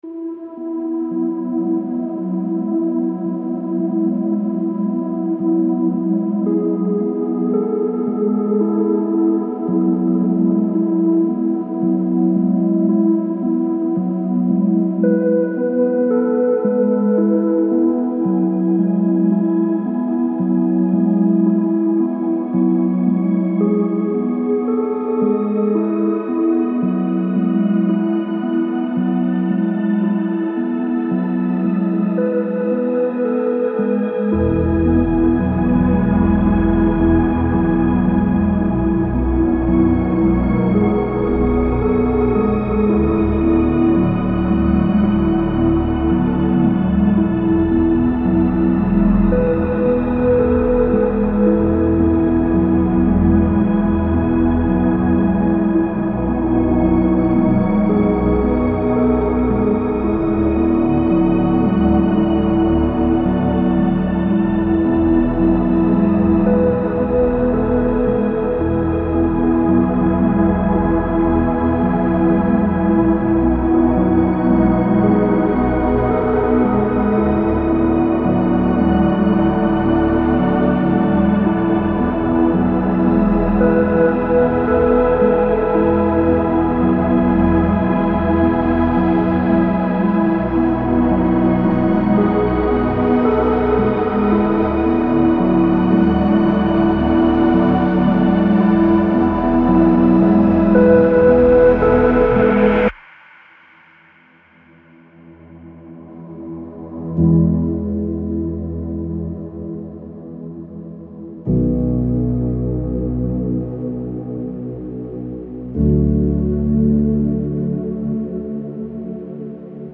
Без слов